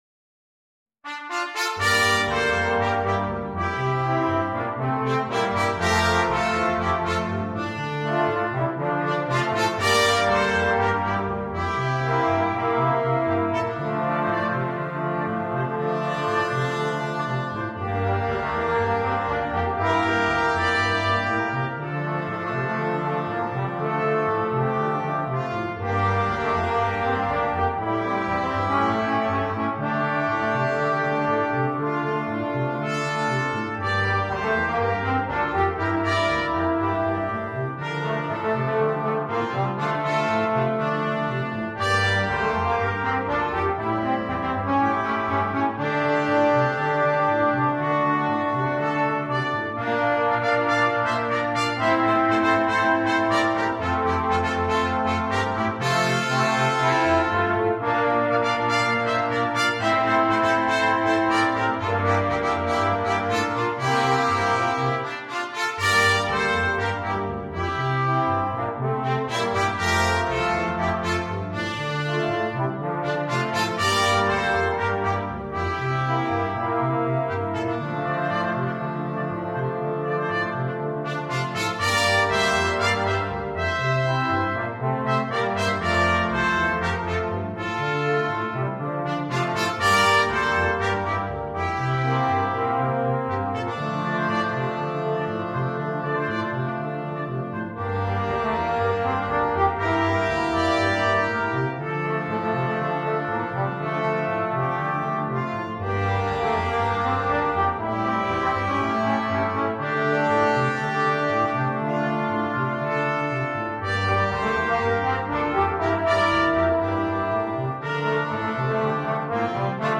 ноты для брасс-бэнда